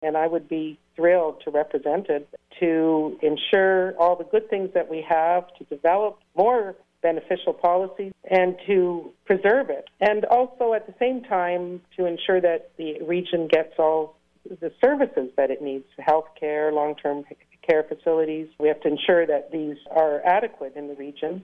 What you will be hearing is audio from an interview recorded May 22nd of 2022.